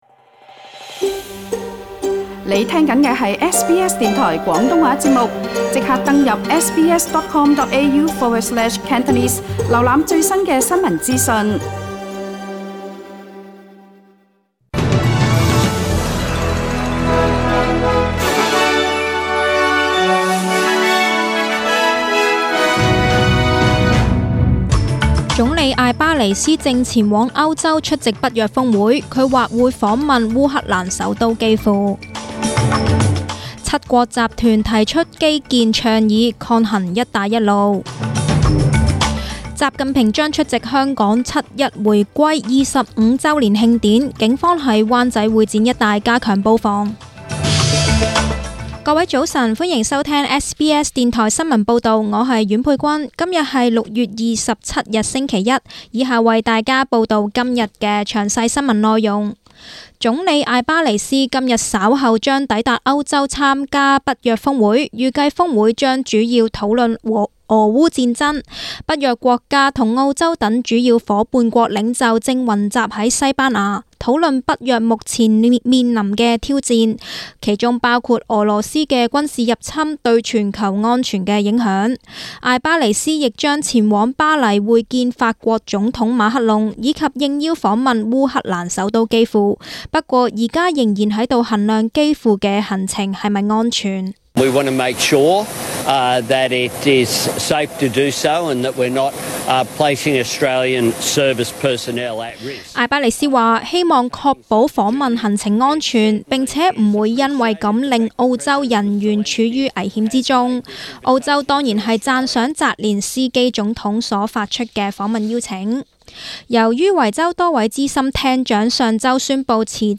SBS中文新闻 （6月27日）